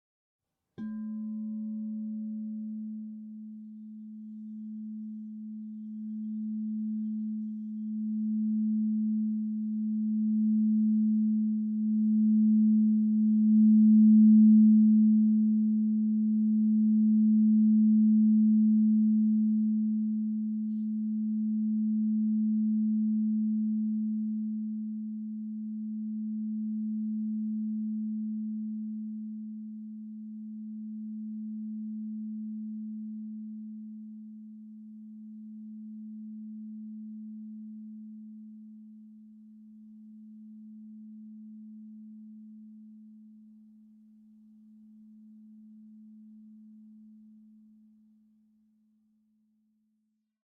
Meinl Sonic Energy 12" white-frosted Crystal Singing Bowl A3, 432 Hz, Brow Chakra (CSB12A3)
The white-frosted Meinl Sonic Energy Crystal Singing Bowls made of high-purity quartz create a very pleasant aura with their sound and design.